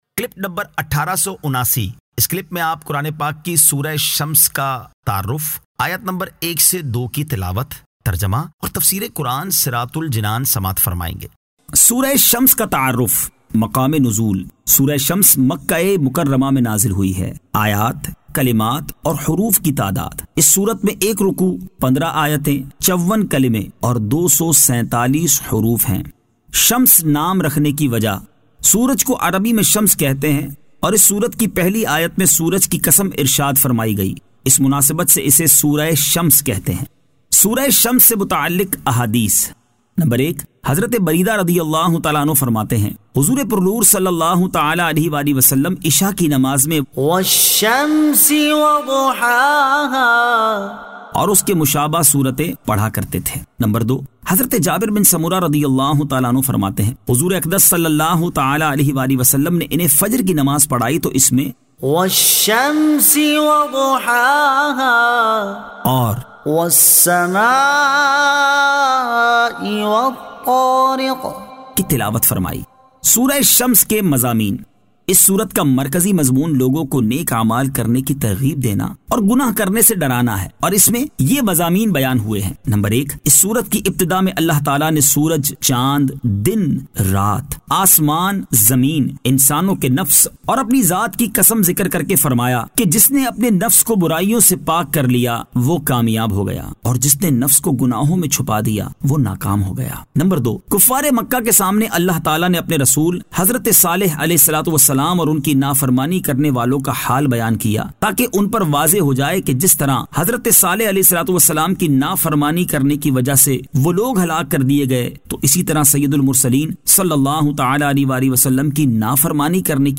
Surah Ash-Shams 01 To 02 Tilawat , Tarjama , Tafseer